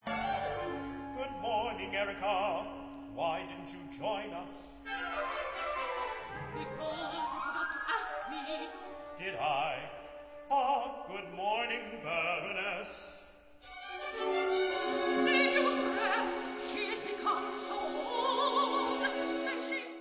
Recording: OPERA